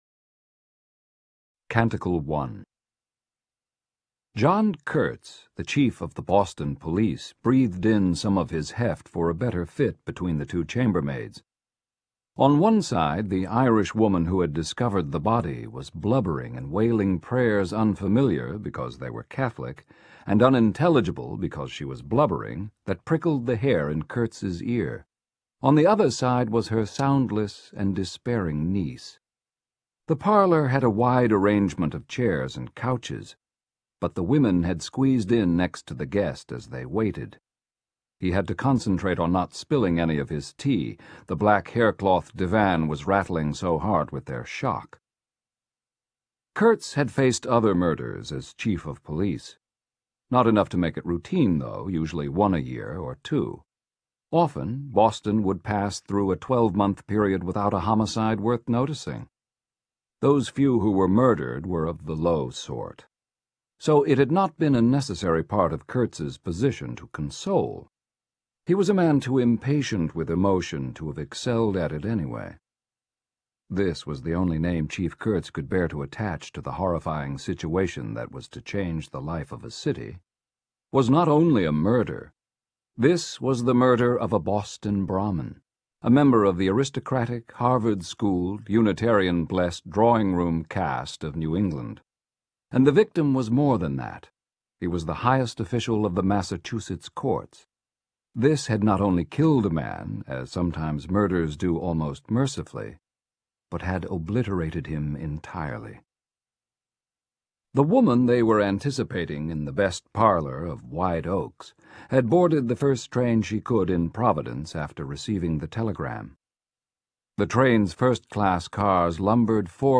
THE POE SHADOW Audio Book